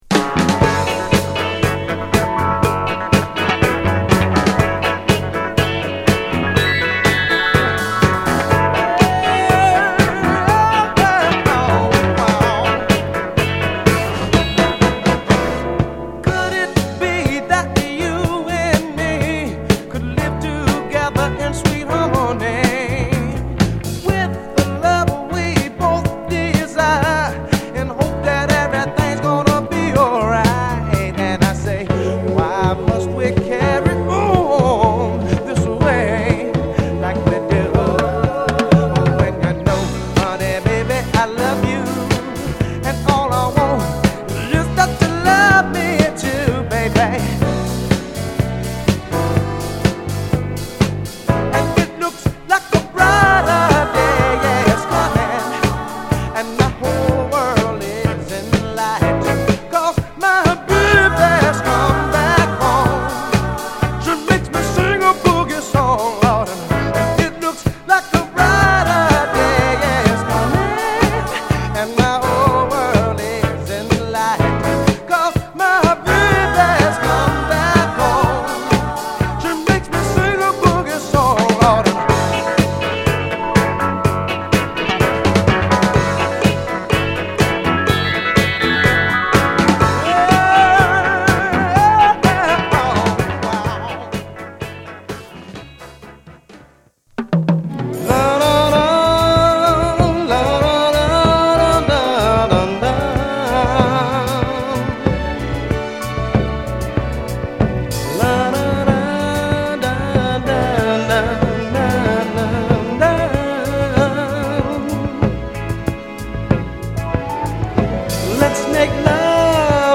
R&B、靈魂樂
ホッコリしたメロウディが心地よいミディアムA3
メロウからダンサブルな曲まで、素晴らしい仕上がり！